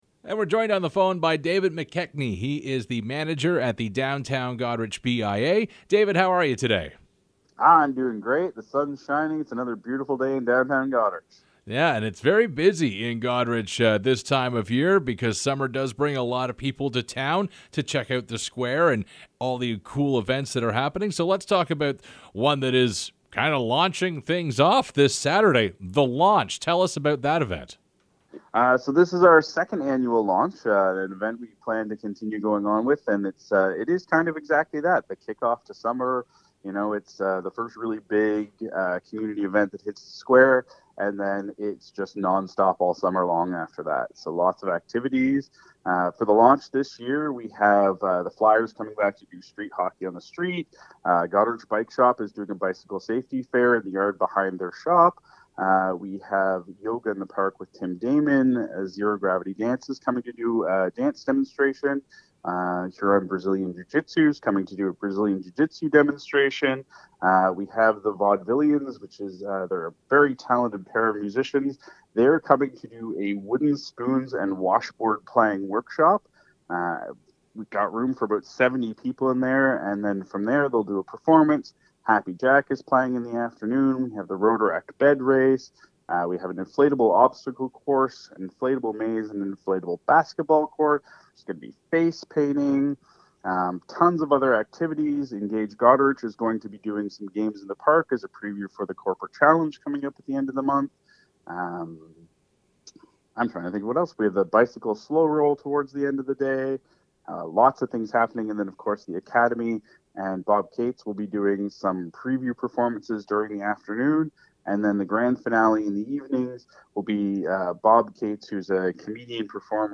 Goderich BIA: Interview